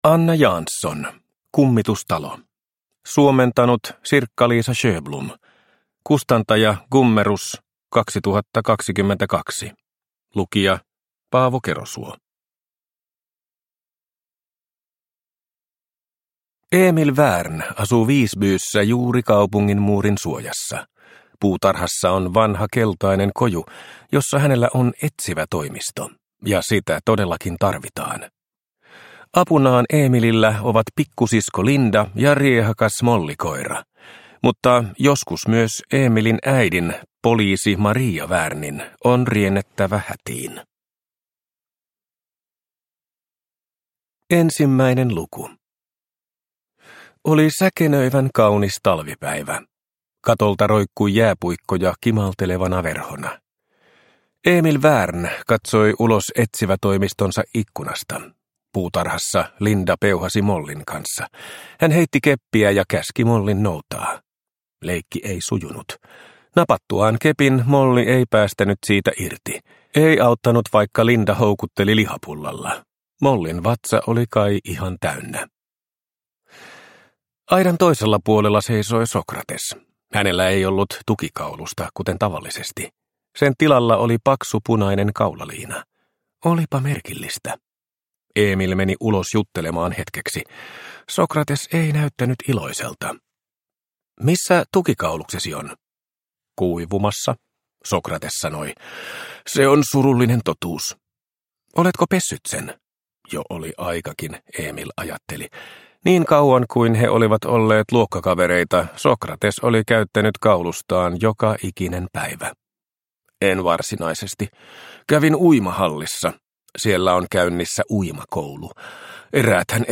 Kummitustalo – Ljudbok – Laddas ner